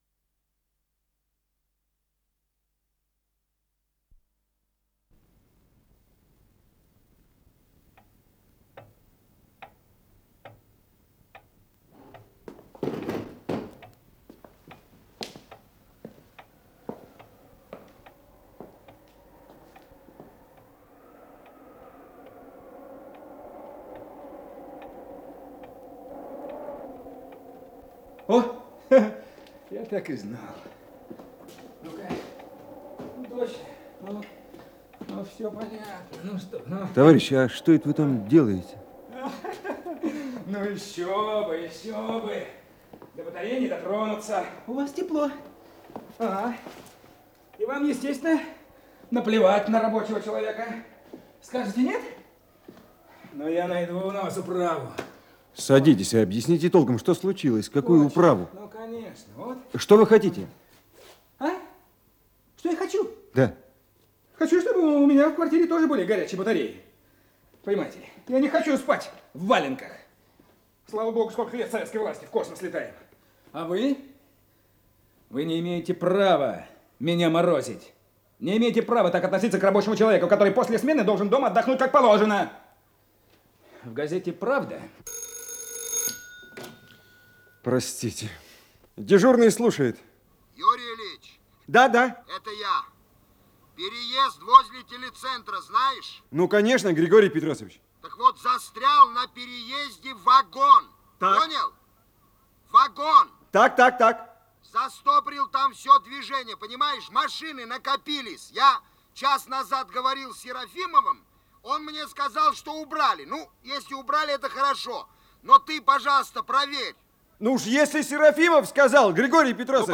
Исполнитель: Артисты московских театров
Радиоспектакль